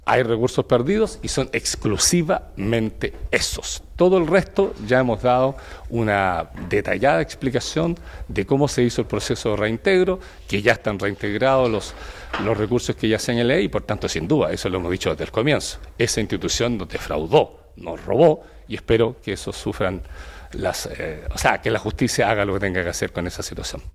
Con sobrios aplausos de su equipo, dio por concluida el Gobernador de Los Lagos, Patricio Vallespín, su última actividad de prensa, con la entrega de detalles del denominado Informe de Traspaso de Mando que se concretará el próximo lunes, para dar paso a la administración de Alejandro Santana en el Gobierno Regional.